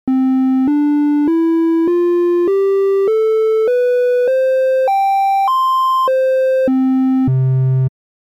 三角波(Triangle)−正弦波に近いものの、少し倍音を含む波形。派手でもなくという感じ。